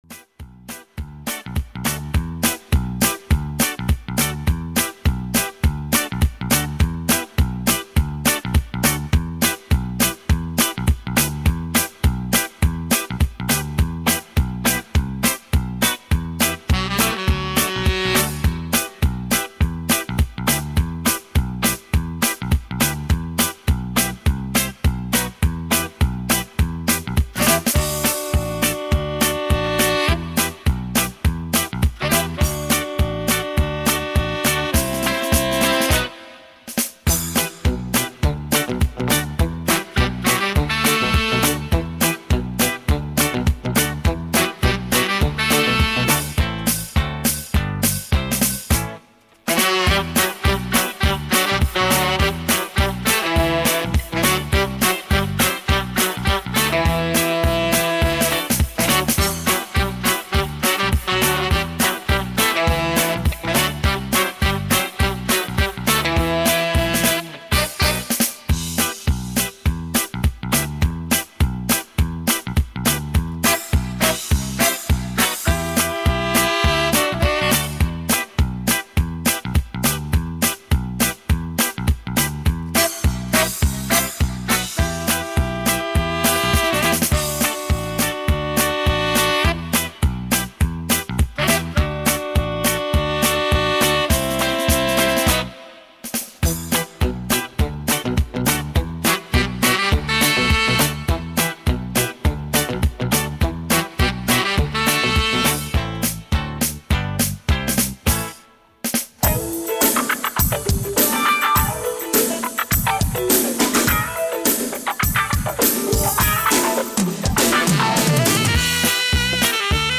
Зажигательная песня Конкурса